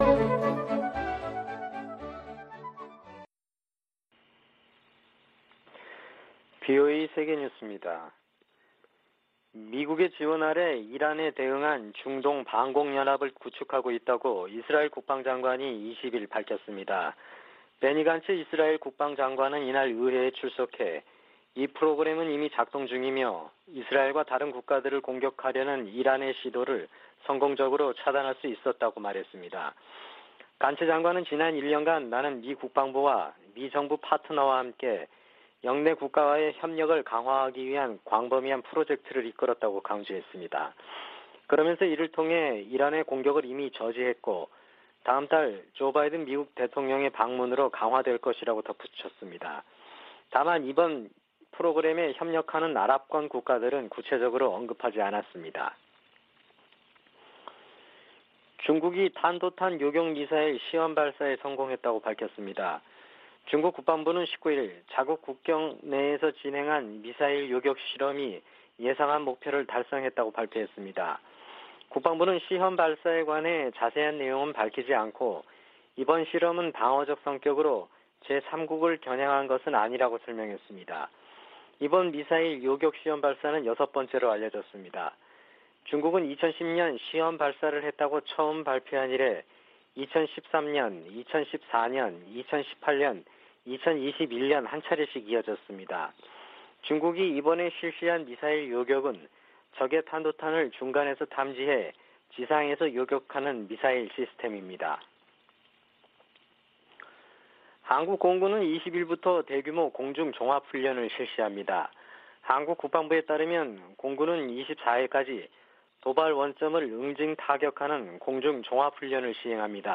VOA 한국어 아침 뉴스 프로그램 '워싱턴 뉴스 광장' 2022년 6월 21일 방송입니다. 미국의 핵 비확산 담당 고위 관리가 오는 8월 NPT 재검토 회의에서 북한 문제를 다룰 것을 요구했습니다. 북한의 7차 핵실험에 관해, 정치적 효과를 극대화하는 데 시간이 걸릴 수 있다는 관측이 나오고 있습니다. 유엔의 의사결정 구조 한계 때문에 북한의 행동을 바꾸기 위한 국제적 단합에 제동이 걸릴 것으로 미 의회조사국이 진단했습니다.